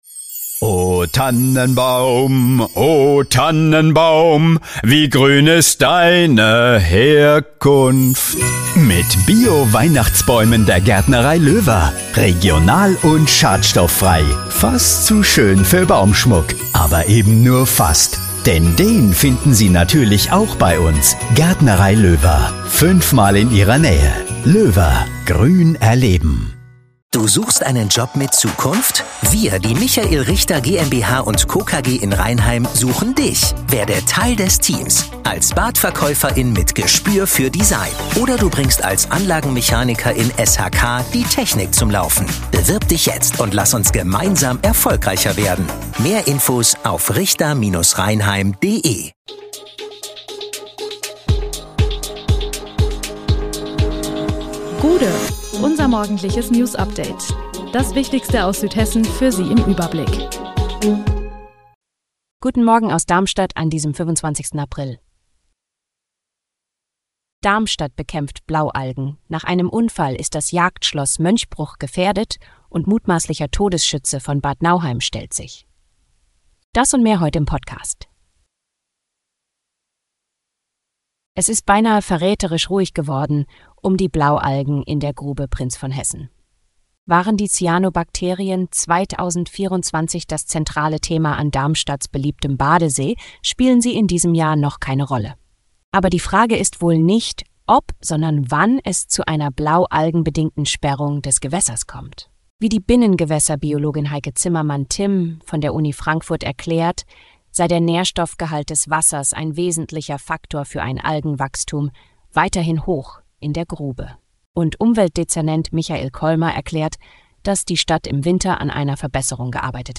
Der Podcast am Morgen für die Region!
Nachrichten